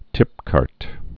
(tĭpkärt)